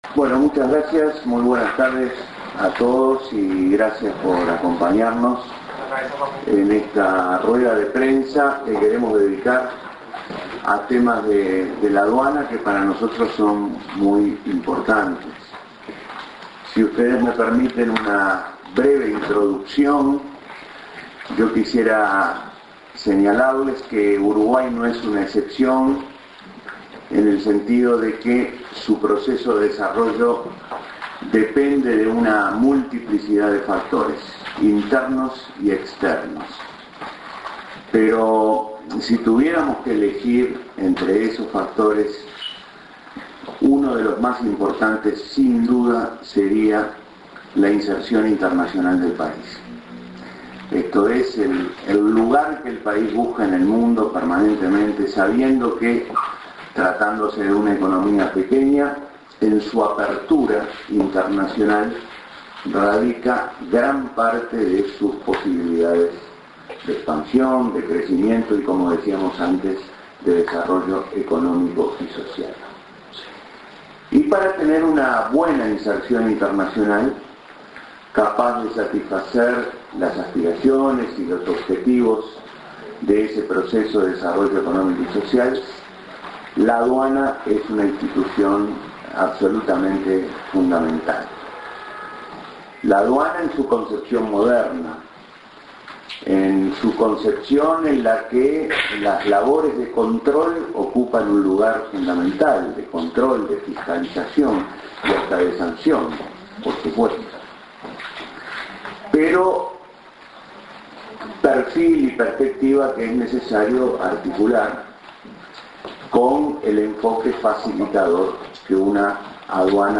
Palabras del Ministro de Economía y Finanzas, Danilo Astori, en conferencia de prensa tras reunirse con el Director Nacional de Aduanas, Luis Salvo.